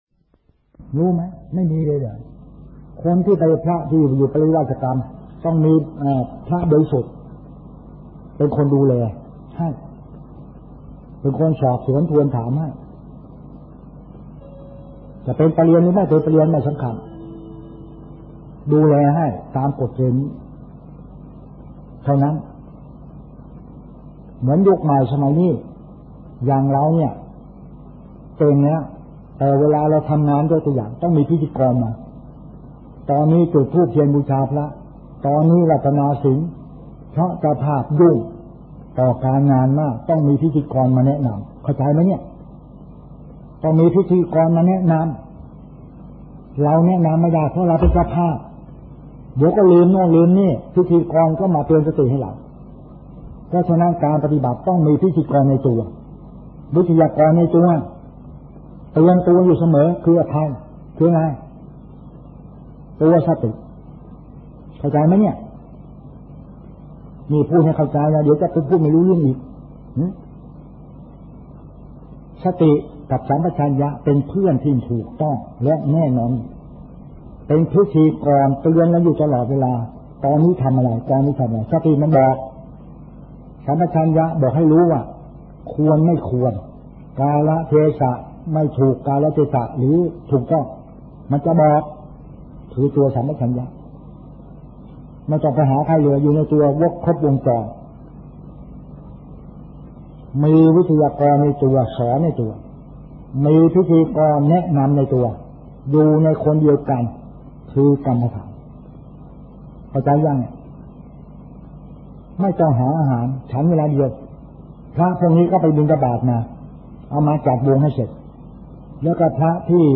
หลวงพ่อจรัญ ฐิตธฺมโม วัดอัมพวัล จ.สิงห์บุรี - เสียงธรรม - ธรรมะไทย